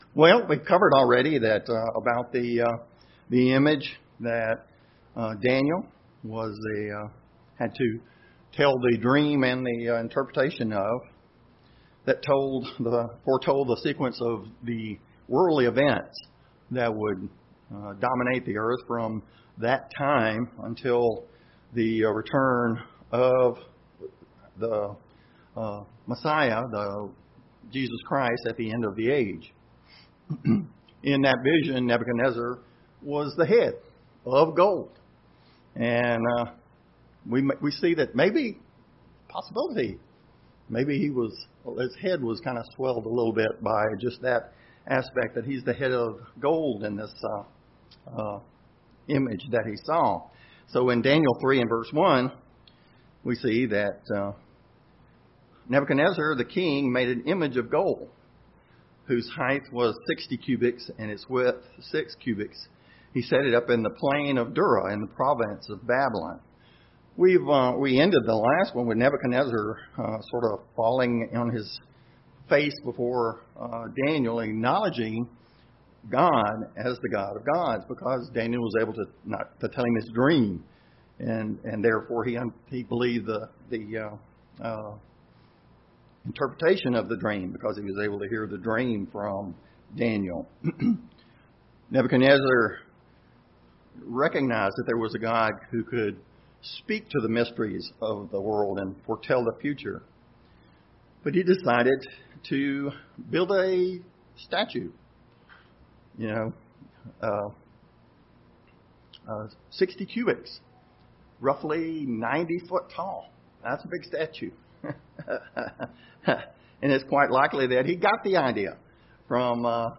Sermons
Given in Laurel, MS New Orleans, LA